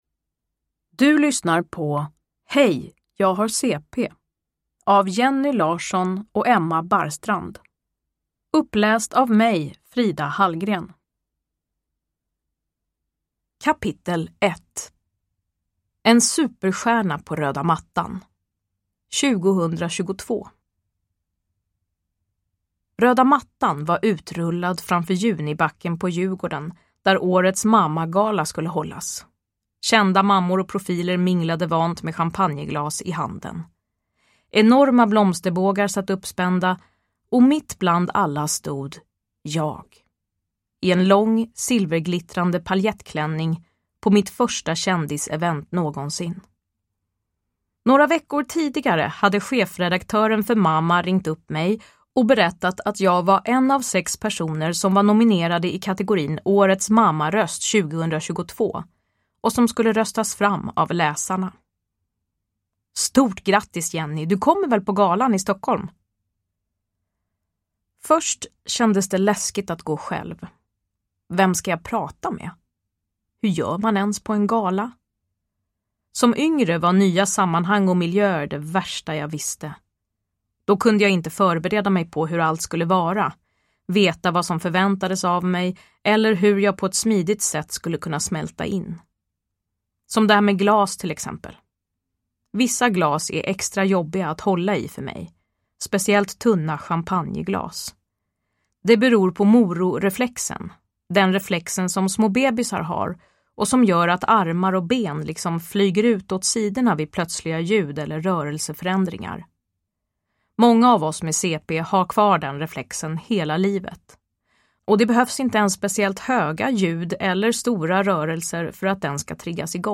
Hej jag har CP! – Ljudbok
Uppläsare: Frida Hallgren